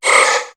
Cri de Tarsal dans Pokémon HOME.